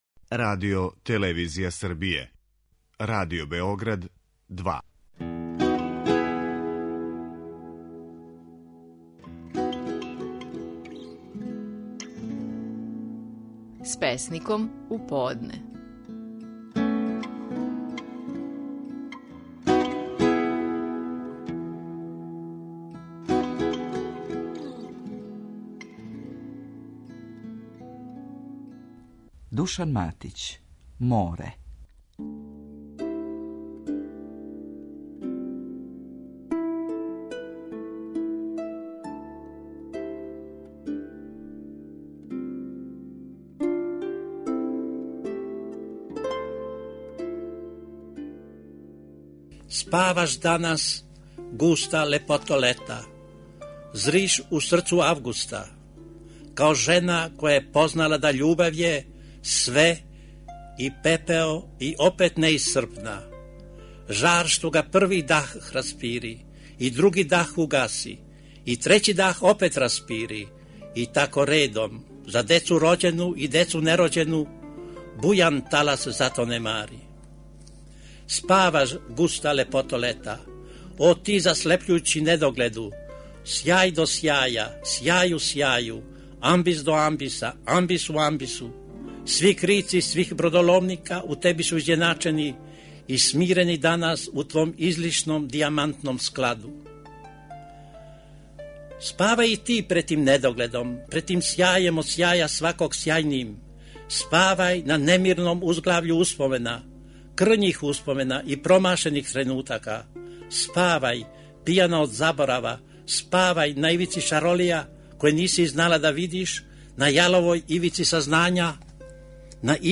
Стихови наших најпознатијих песника, у интерпретацији аутора
Душан Матић говори своју песму „Море".